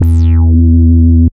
71.03 BASS.wav